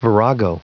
Prononciation du mot virago en anglais (fichier audio)
Prononciation du mot : virago